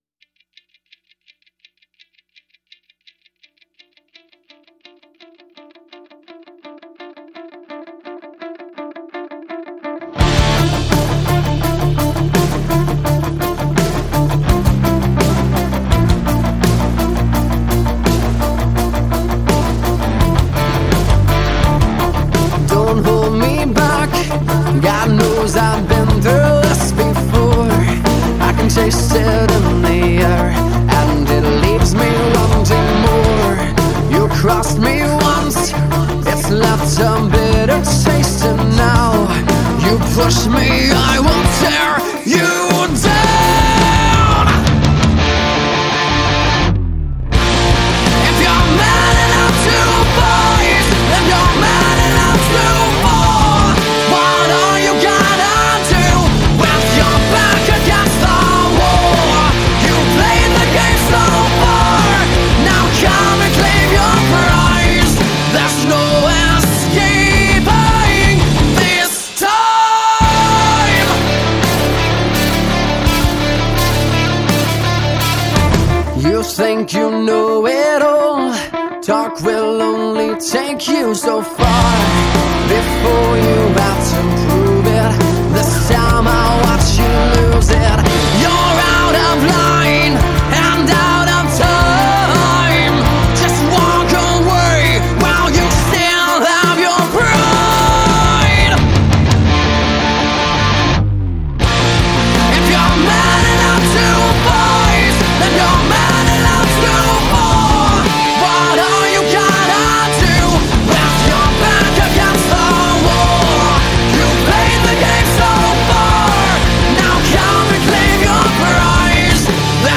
Жанр: Rock